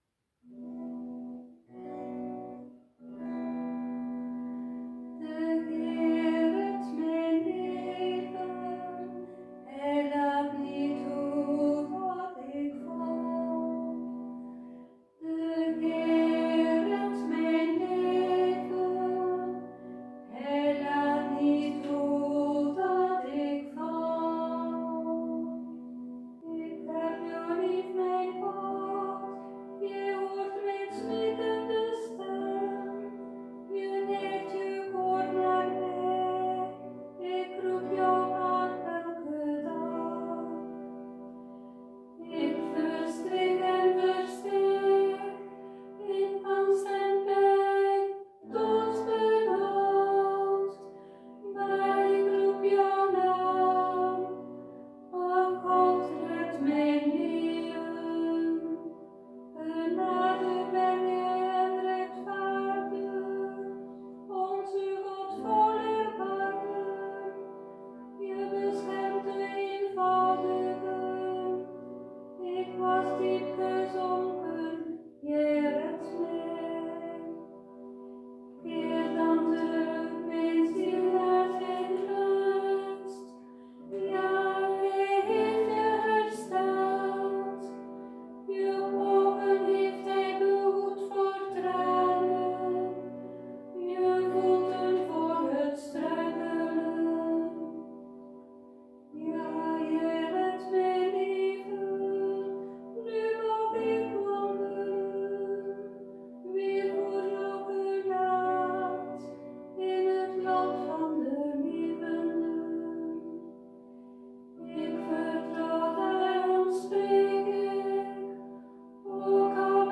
met citerbegeleiding